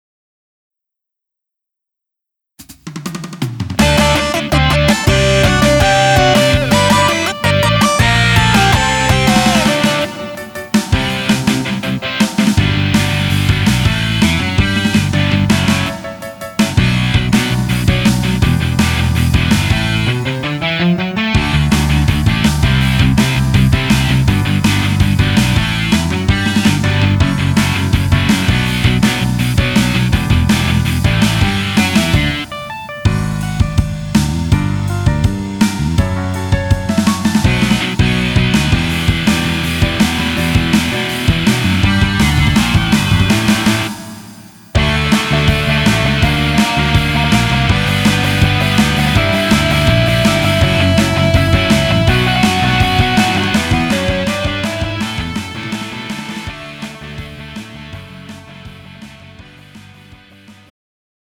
음정 원키 3:52
장르 가요 구분 Pro MR